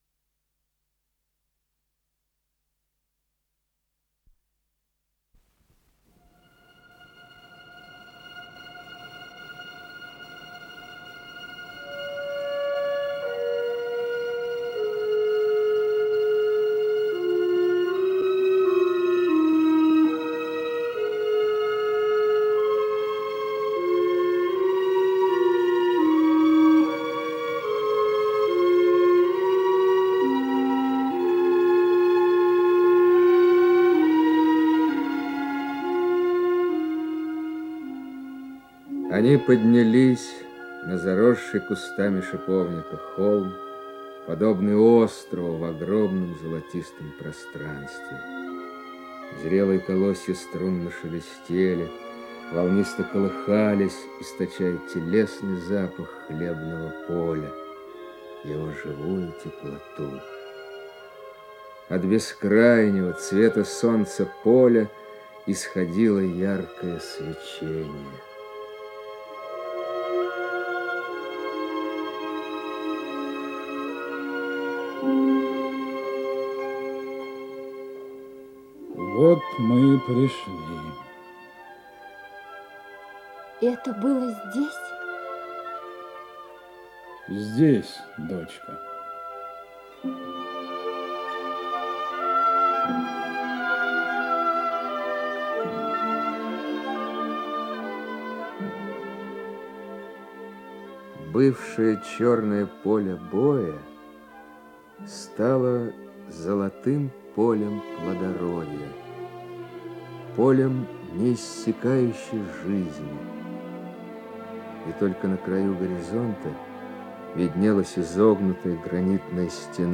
Инсценированные страницы повести